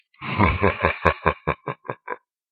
Laugh 1
laugh laughing laughter sound effect free sound royalty free Funny